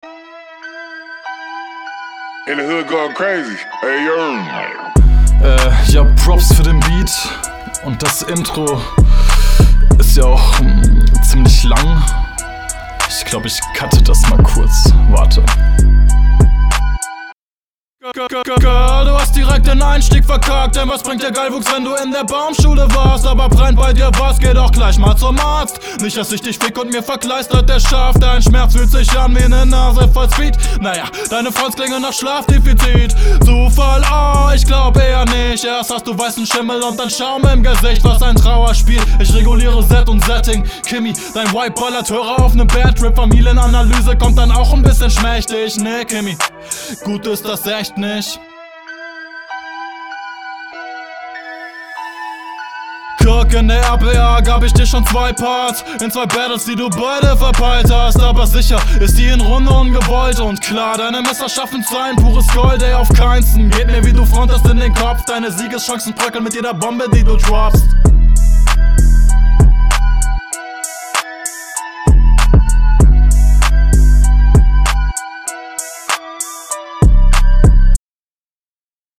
Uuuh du hast hier einen echt geilen Flow, deine Betonung kommt hier auch echt nice.
Zur Mische, Beat ist zu leise bzw Vocals zu laut.